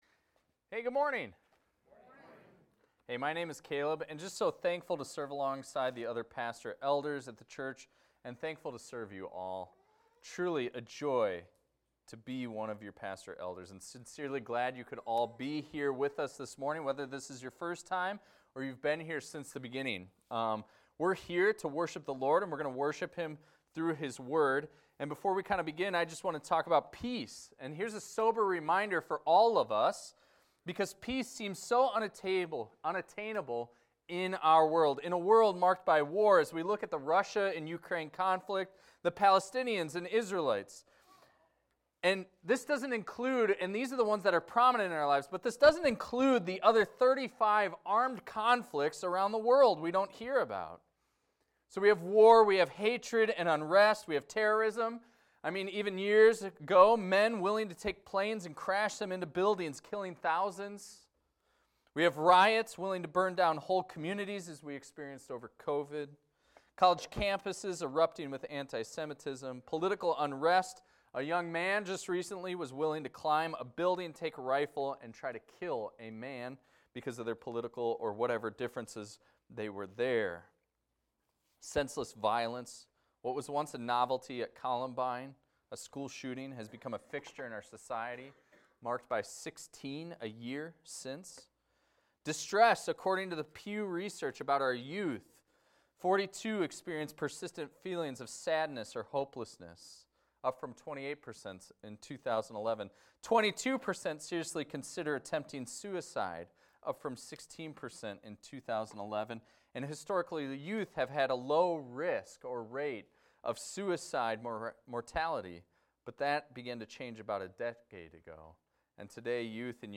This is a recording of a sermon titled, "The Lord of Peace."